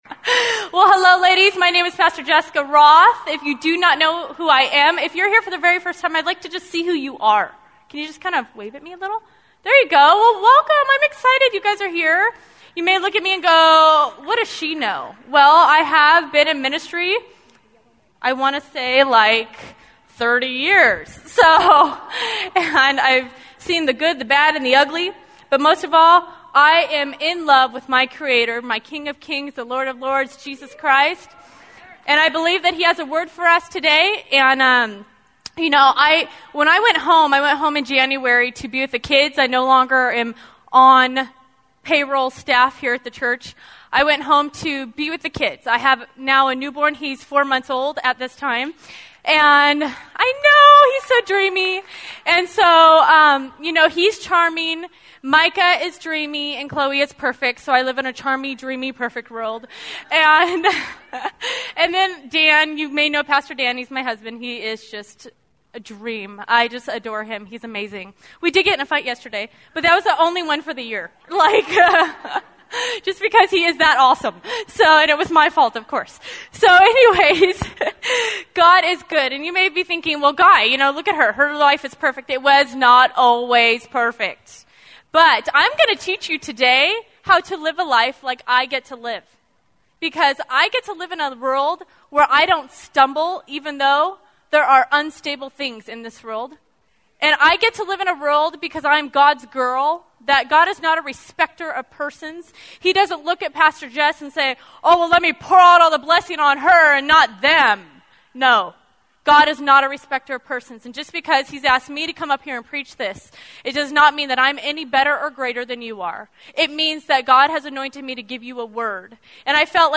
Welcome to The Rock Church's Archives. Here you can find all of our messages and blogs.